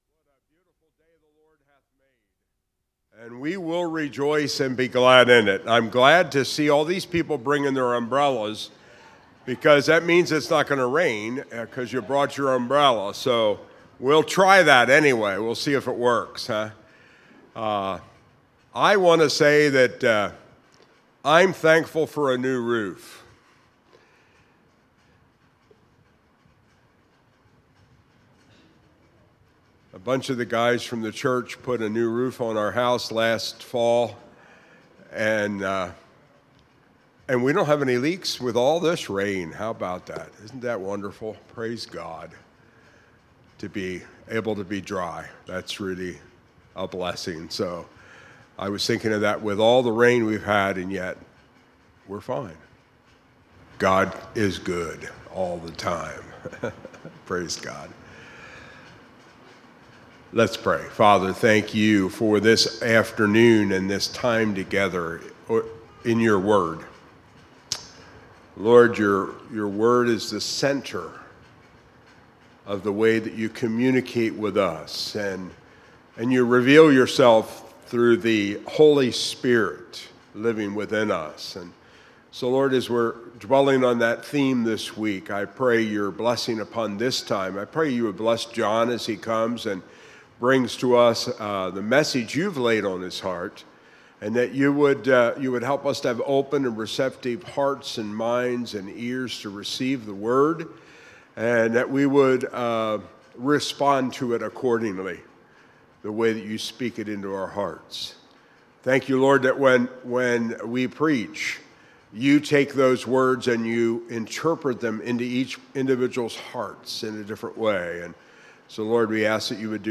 Series: Campmeeting 2025